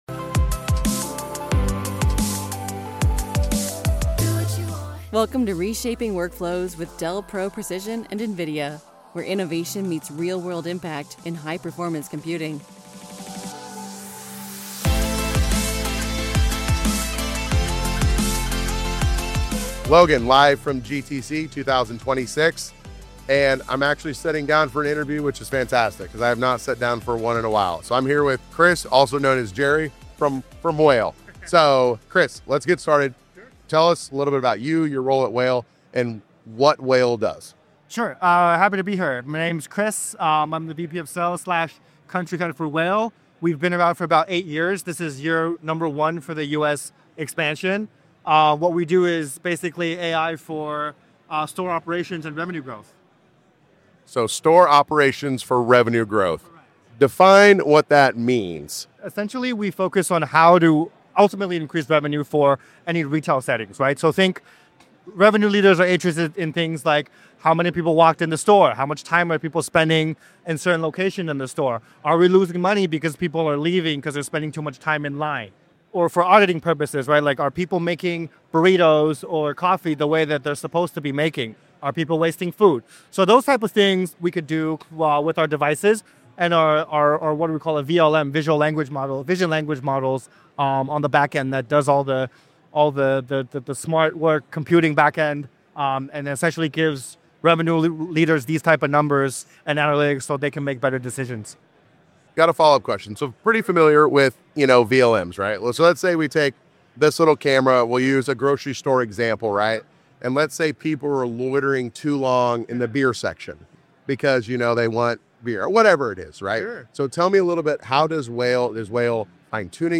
Live from GTC